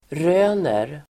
Uttal: [r'ö:ner]